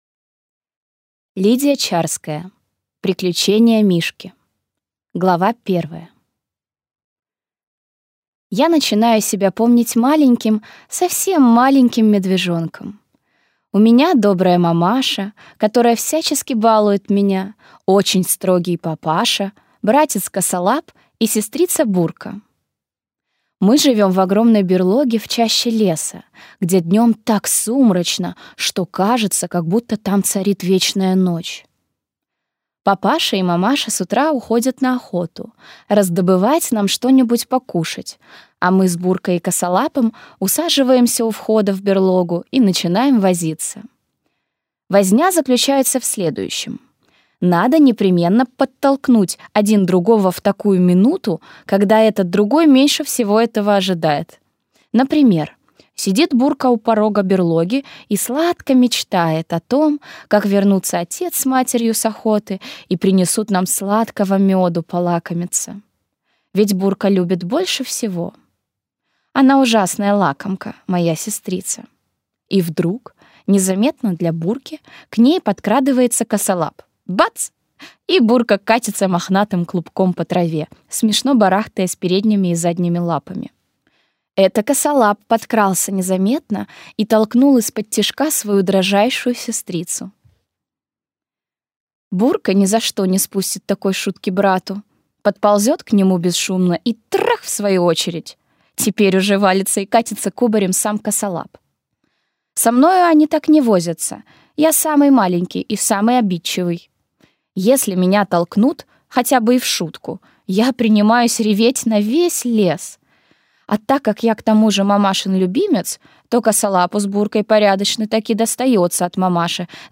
Аудиокнига Приключения Мишки | Библиотека аудиокниг